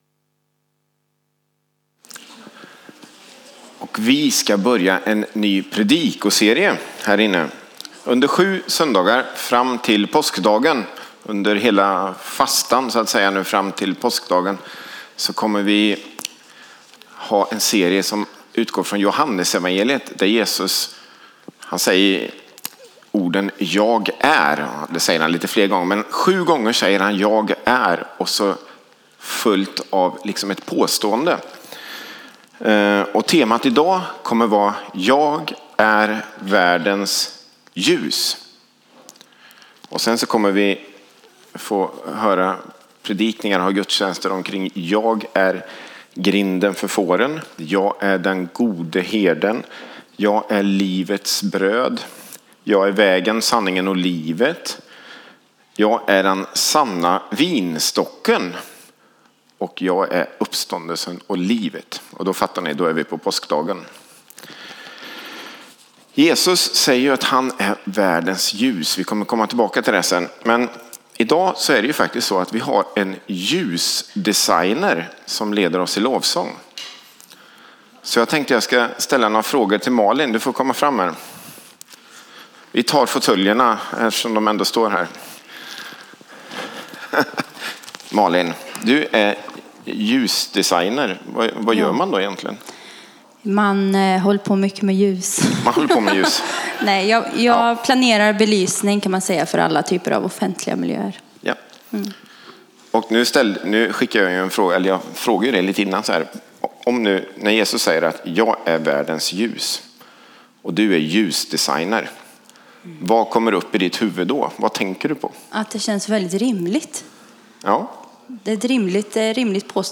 A predikan from the tema "7x Jag är."